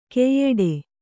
♪ keyyeḍe